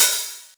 • Open Hat One Shot D Key 27.wav
Royality free open hi hat tuned to the D note. Loudest frequency: 7596Hz
open-hat-one-shot-d-key-27-uQV.wav